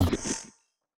Mech Hit Notification 6.wav